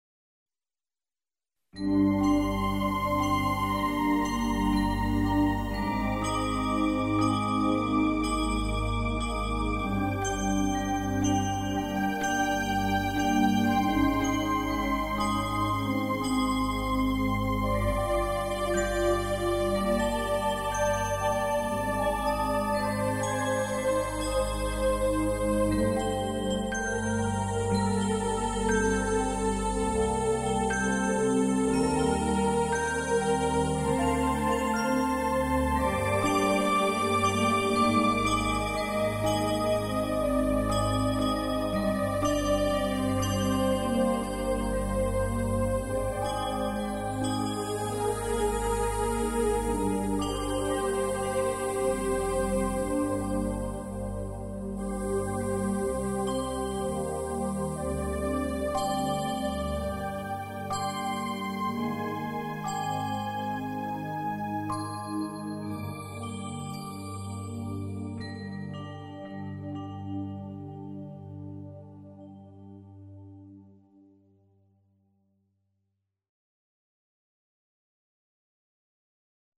融合New Age的靈性與冥想音樂的神性，